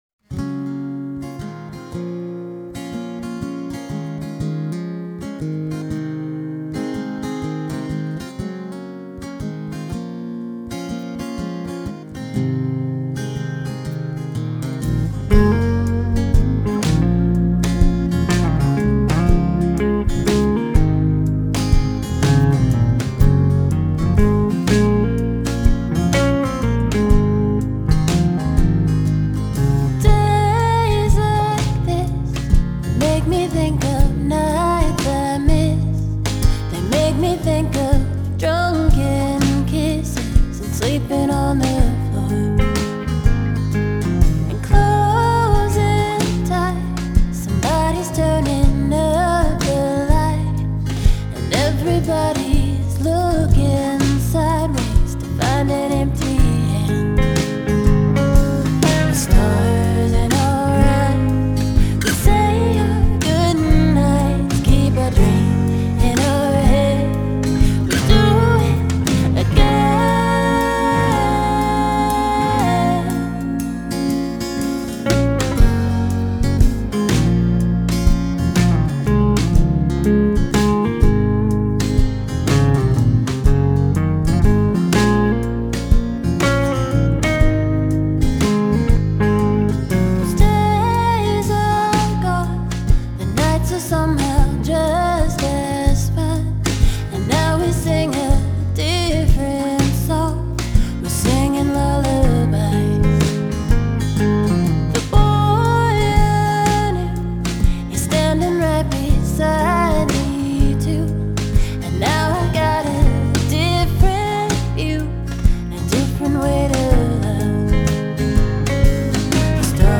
Genre: Folk Pop, Americana, Singer-Songwriter